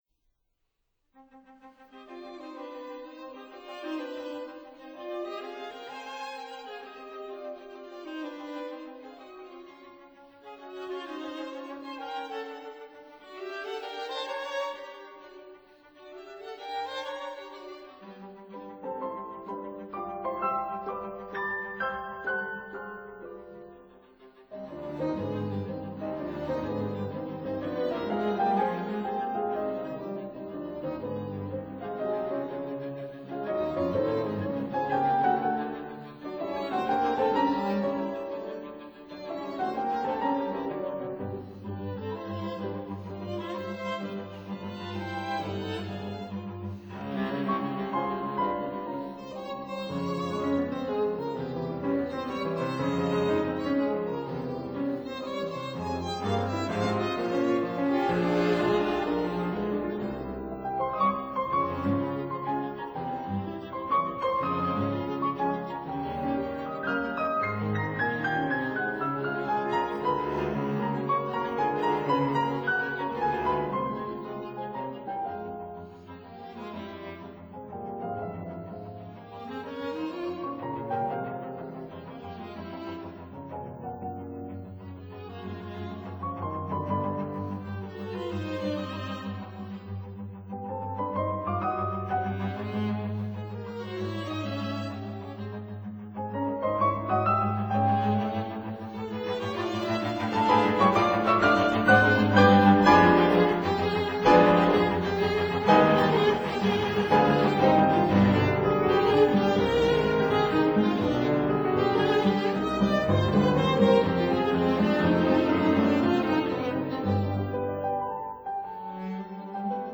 violin
viola
celo